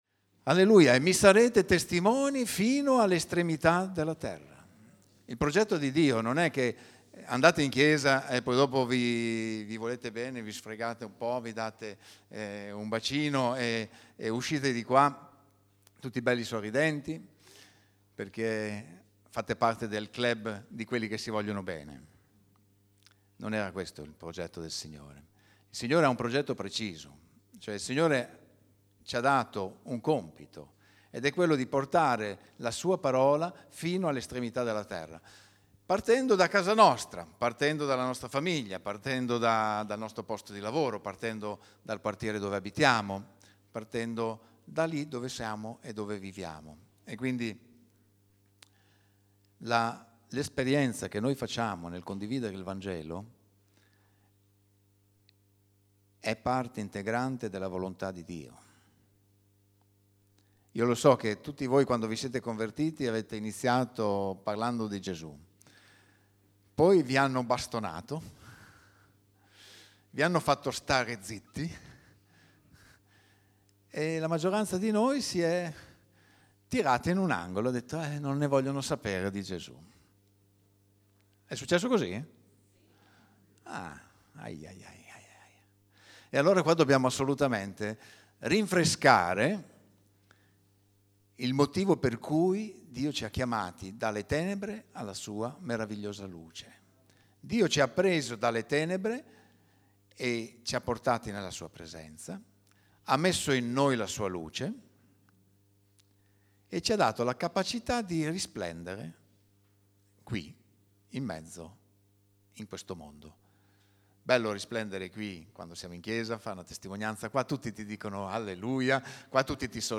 › Pubblicato in Messaggio domenicale